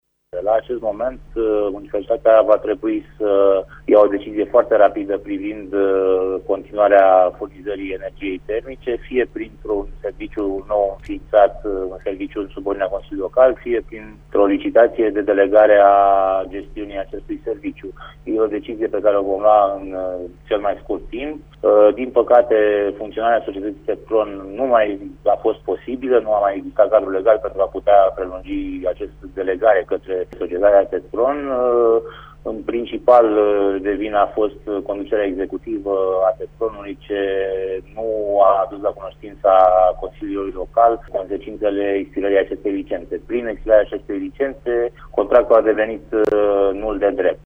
Viceprimarul Ciprian Bucur mai spune că în Consiliul Local va trebui găsită o nouă formulă de funcționare a Tetkron, având în vedere că la ora actuală societatea nu are nici măcar un buget aprobat: